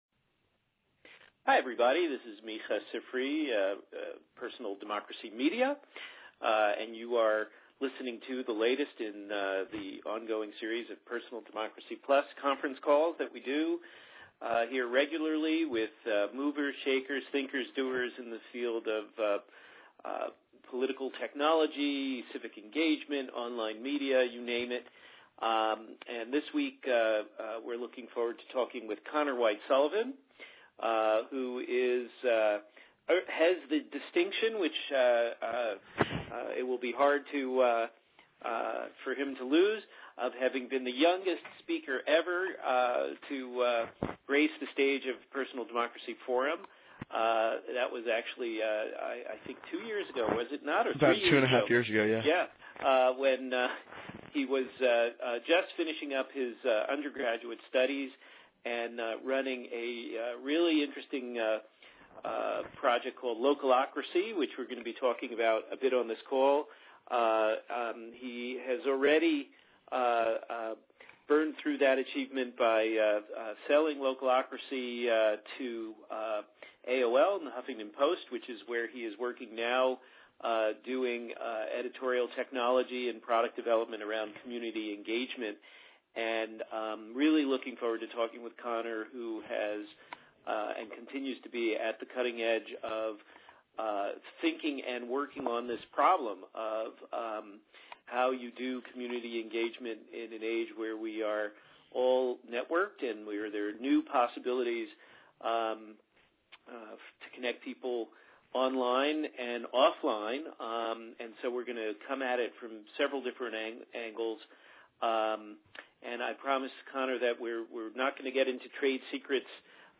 That's the question explored on this call